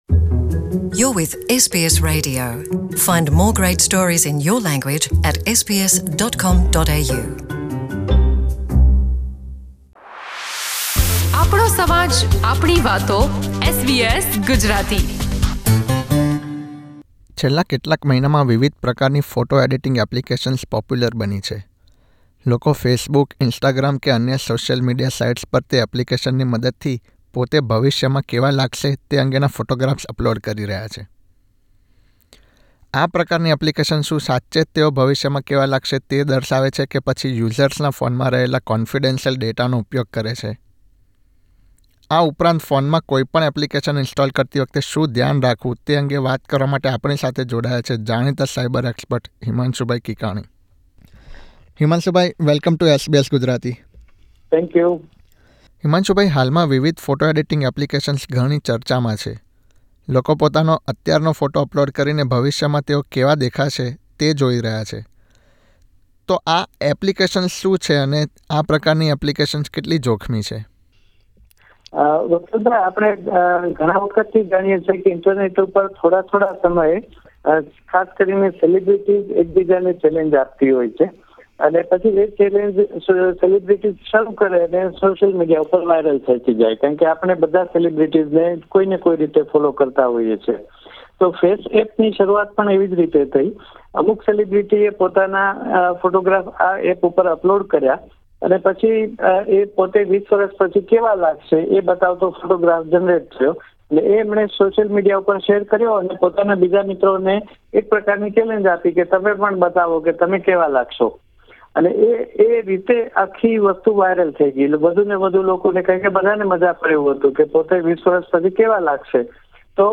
SBS Gujarati સાથેની વાતચીત.